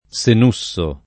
Senusso
Senusso [ S en 2SS o ]